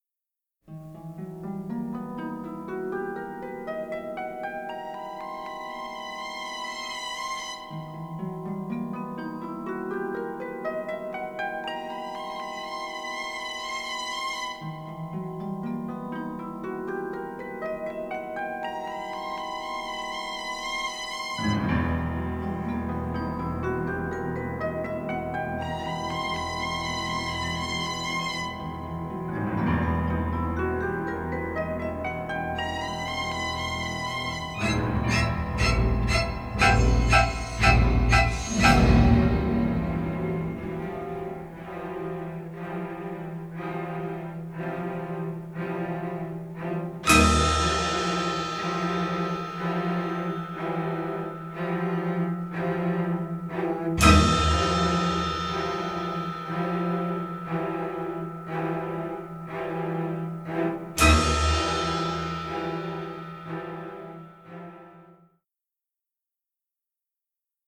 intense string jabs